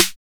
SNARE1.wav